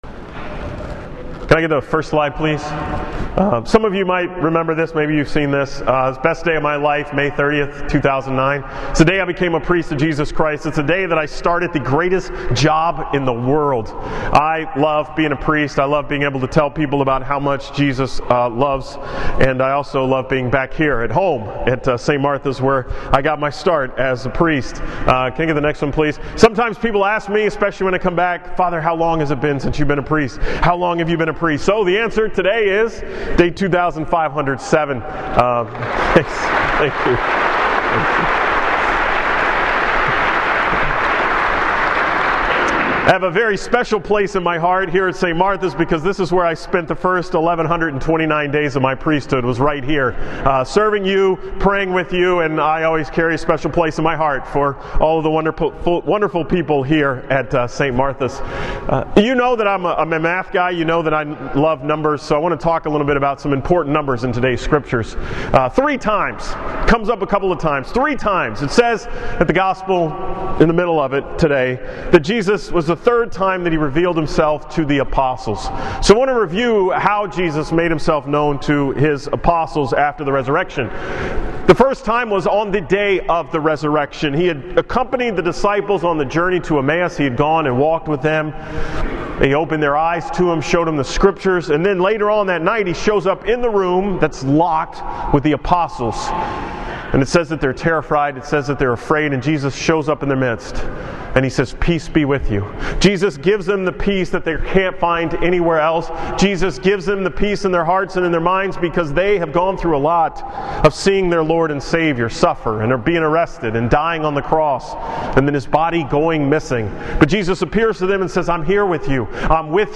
From the 11 am Mass at St. Martha’s on April 10, 2016 Slides shown at Mass: Click Here